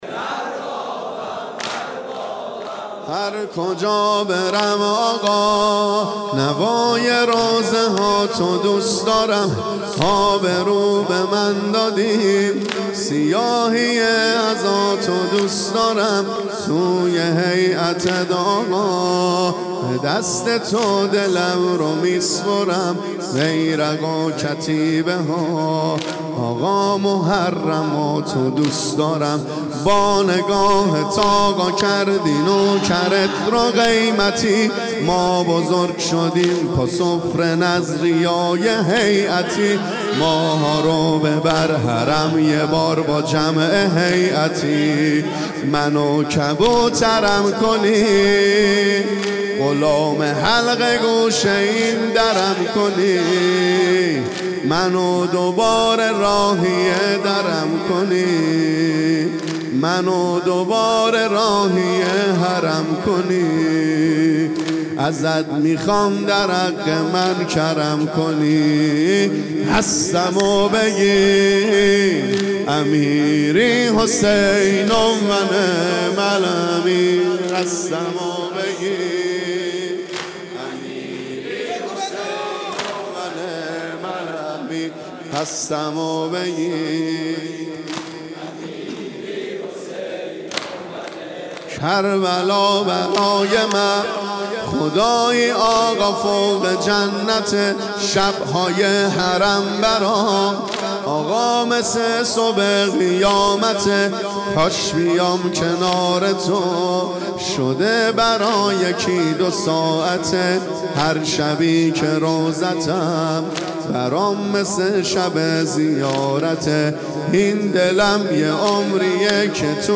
هرکجا برم آقا|یادواره شهدا ۹۵